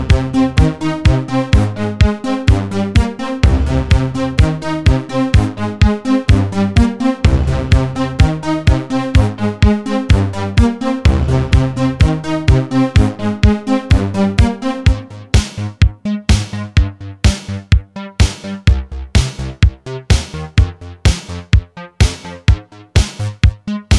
Cut Down With No Backing Vocals Pop (1980s) 3:31 Buy £1.50